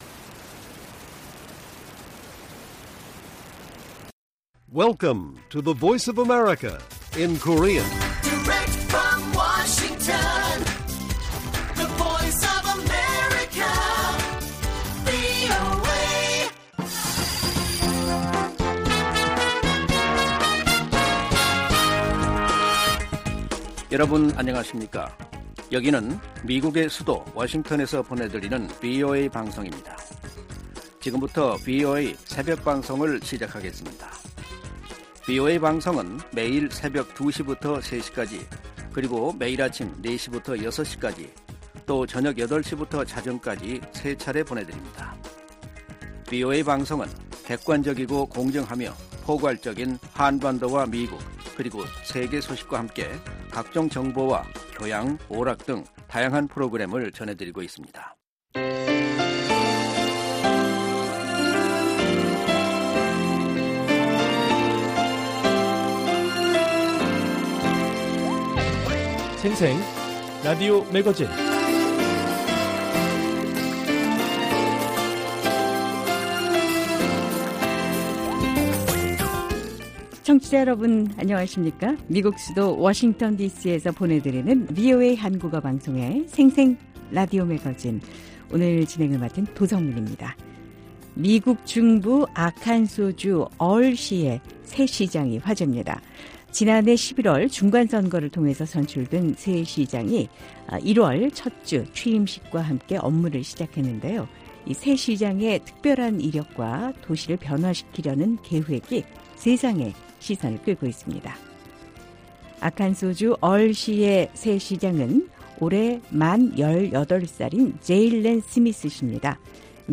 VOA 한국어 방송의 월요일 새벽 방송입니다. 한반도 시간 오전 2:00 부터 3:00 까지 방송됩니다.